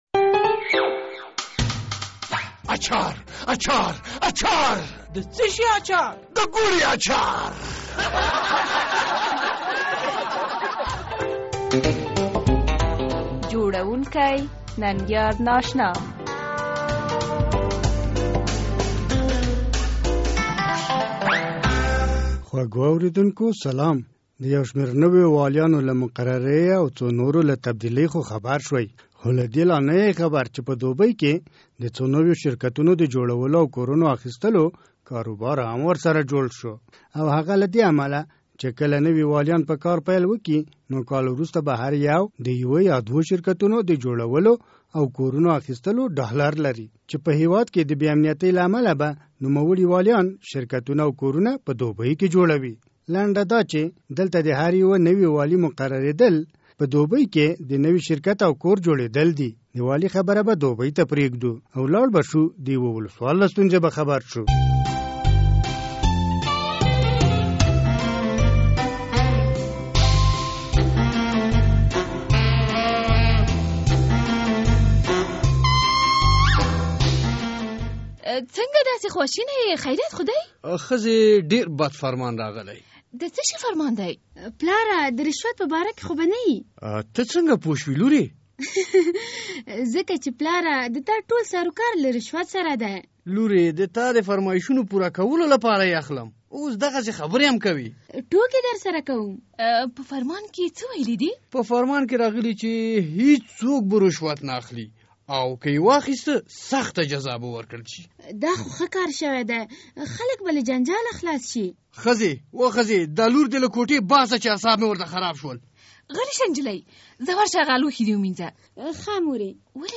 د ازادي راډیو د اوونۍ طنزي خپرونه د ګوړې اچار